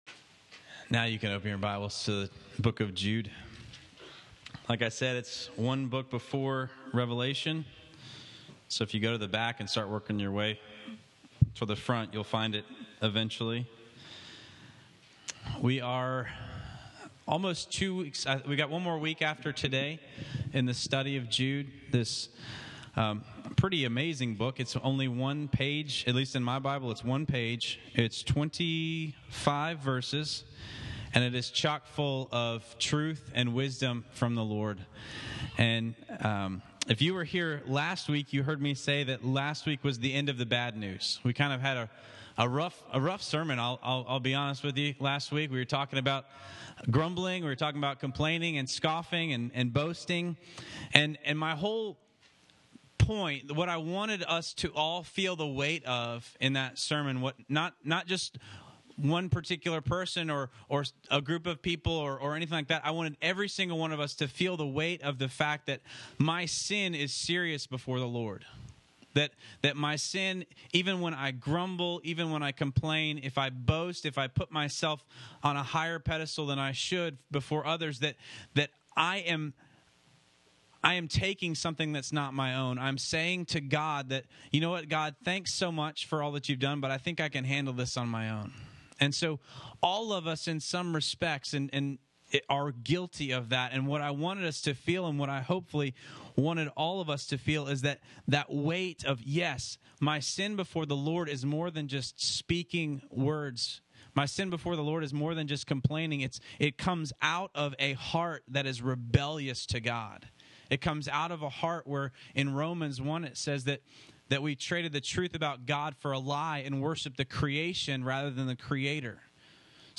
My hope last week was that each and every one of us would walk away from that sermon with the realization that we too may be capable of that same thing.